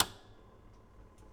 musiic_box_open_L.wav